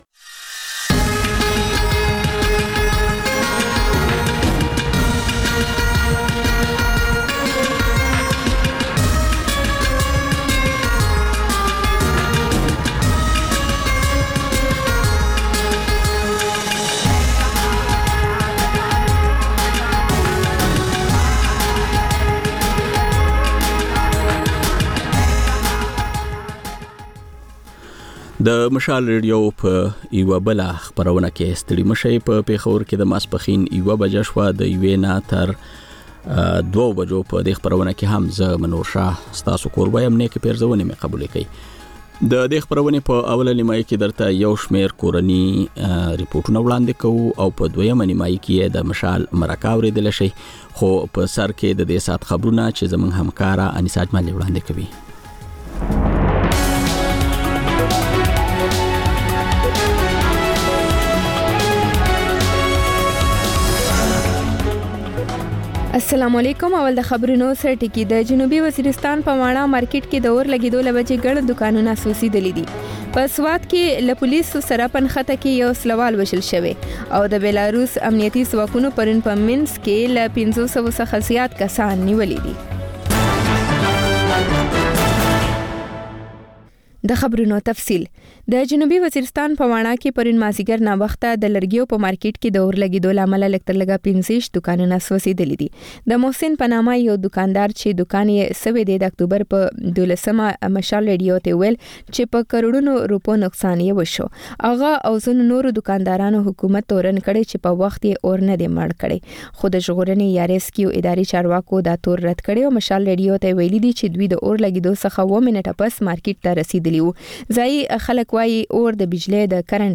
د مشال راډیو لومړۍ ماسپښينۍ خپرونه. په دې خپرونه کې تر خبرونو وروسته بېلا بېل رپورټونه، شننې، مرکې خپرېږي. په دې ګړۍ کې اوونیزه خپرونه هم خپرېږي.